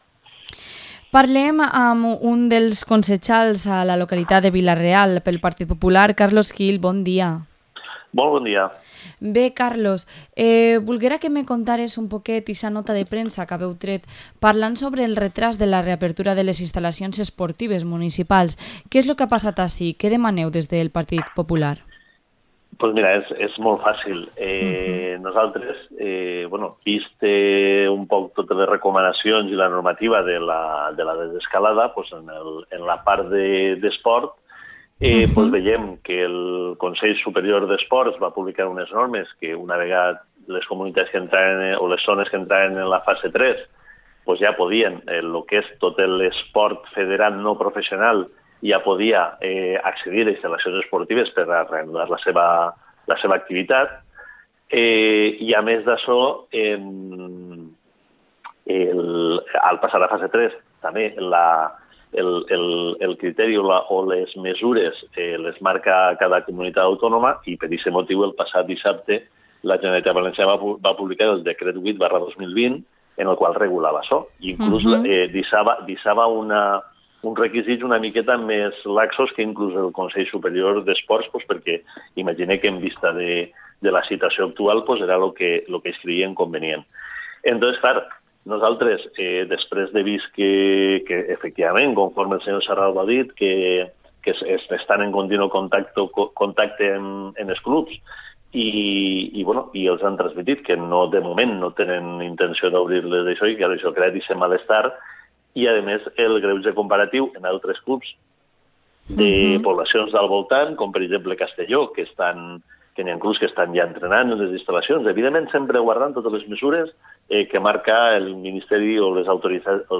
Entrevista al concejal del PP de Vila-real, Carlos Gil